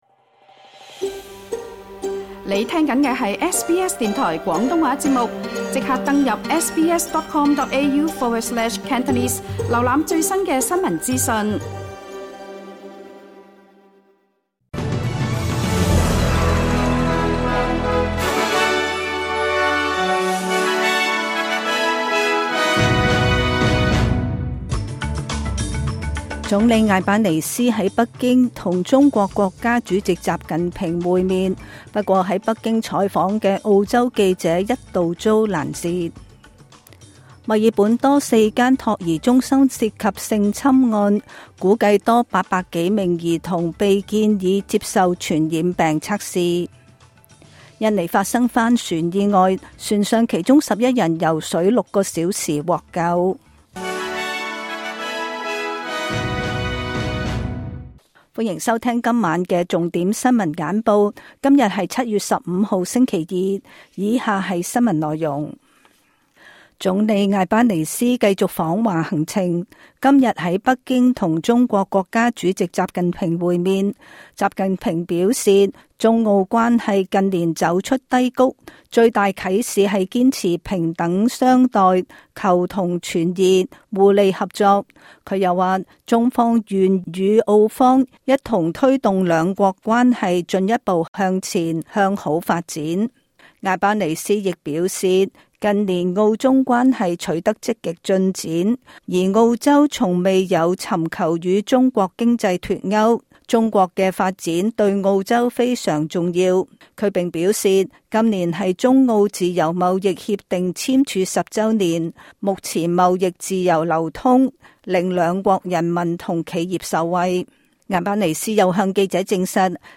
SBS廣東話晚間新聞